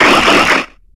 Audio / SE / Cries / VIBRAVA.ogg